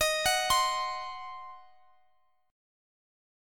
Listen to Ebm6 strummed